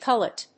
音節cul・let 発音記号・読み方
/kˈʌlət(米国英語)/